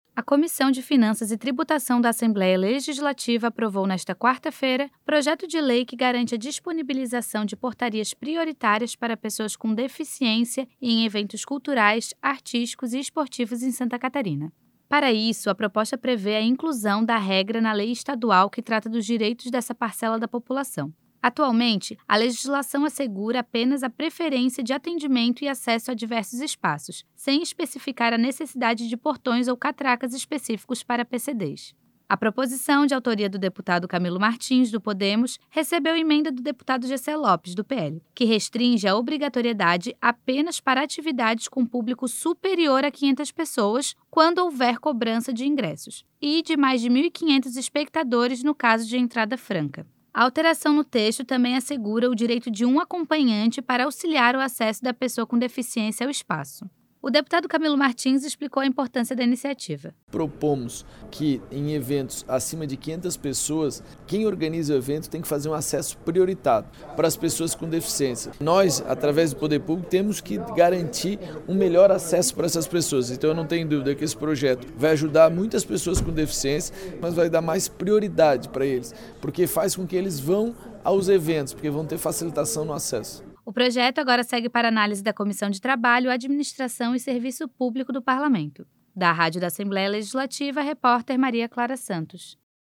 O deputado Camilo Martins, explicou a importância da iniciativa.
Entrevista com:
- deputado Camilo Martins, autor do projeto.